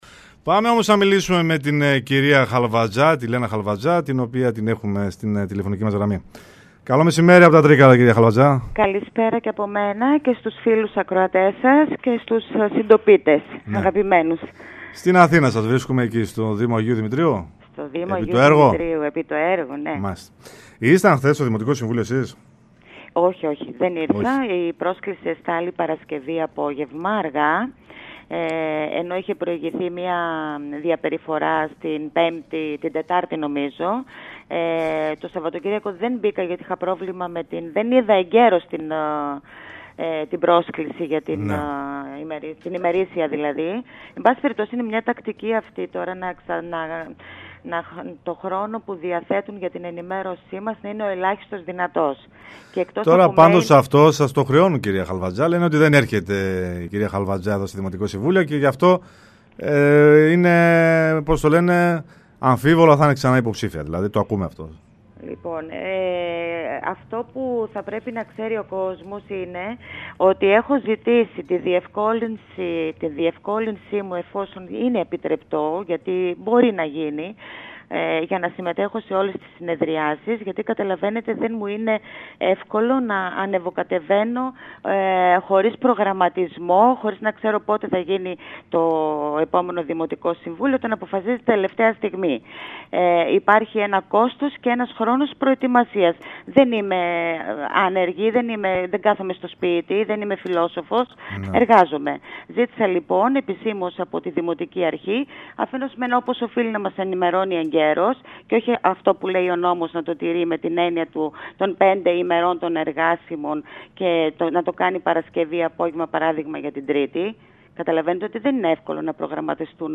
Στη Ραδιοφωνική Λέσχη 97,6 και στην εκπομπή «Ώρα για λέσχη»
μίλησε σήμερα η επικεφαλής της ελάσσονος αντιπολίτευσης Λένα Χαλβατζά.